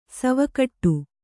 ♪ sava kaṭṭu